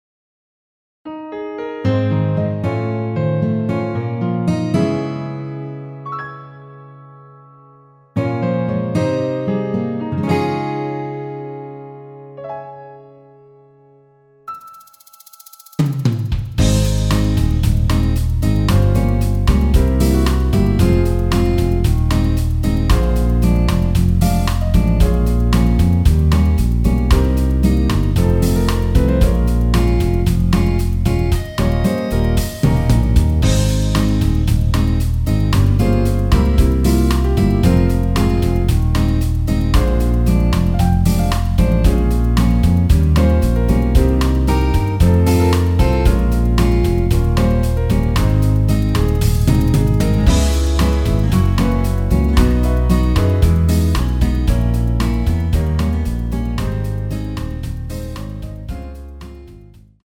Ab
◈ 곡명 옆 (-1)은 반음 내림, (+1)은 반음 올림 입니다.
앞부분30초, 뒷부분30초씩 편집해서 올려 드리고 있습니다.